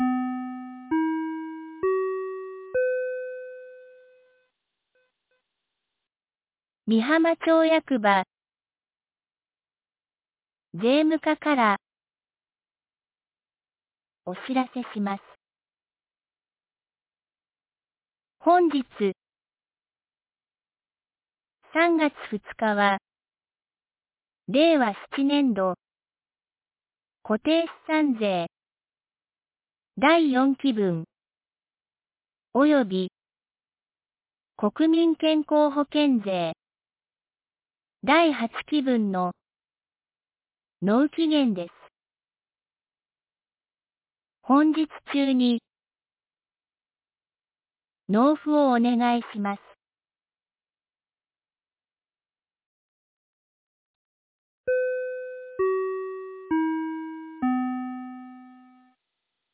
美浜町放送内容 2026年03月02日07時46分 （町内放送）税の納期限 | 和歌山県美浜町メール配信サービス
2026年03月02日 07時46分に、美浜町より全地区へ放送がありました。